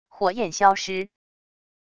火焰消失wav音频